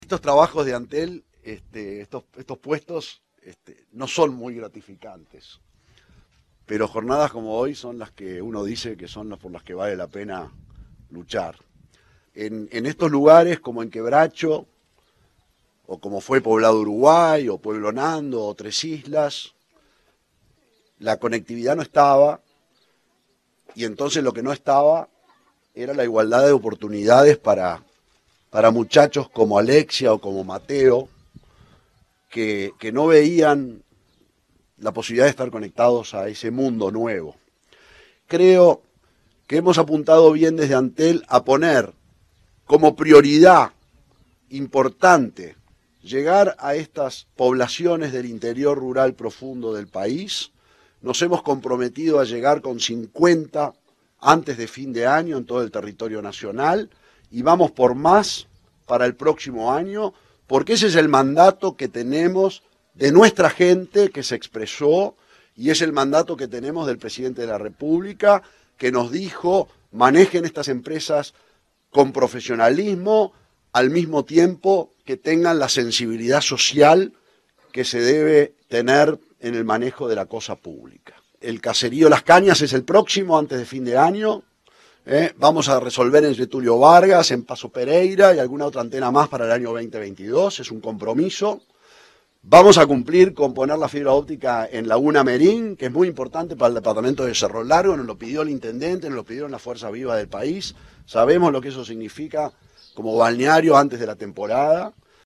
Declaraciones del presidente de Antel, Gabriel Gurméndez